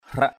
/hraʔ/ (d.) cây bìm bìm. kau mâk katak hrak cih thaik payua (NMM) k~@ mK ktK hK c{H =EK py&% em lấy nhựa bìm bìm vẽ hình gửi sang.
hrak.mp3